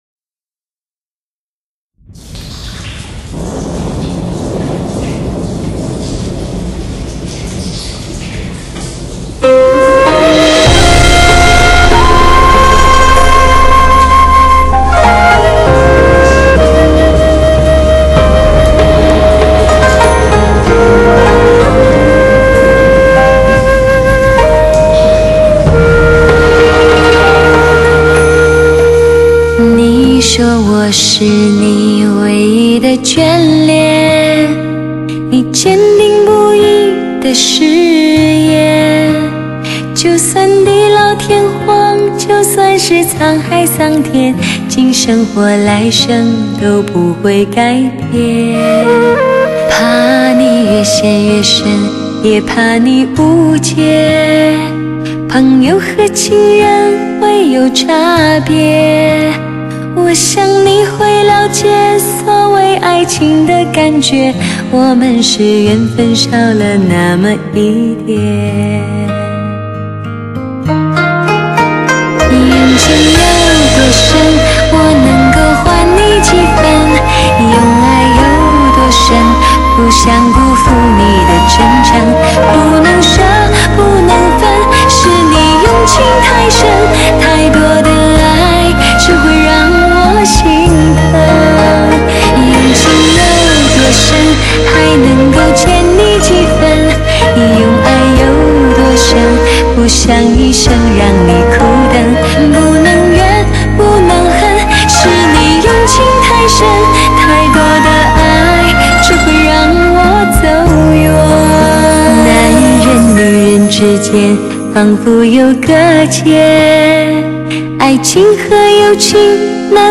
真正实现车内空间6.1Simulation 360度环绕HI-FI音效！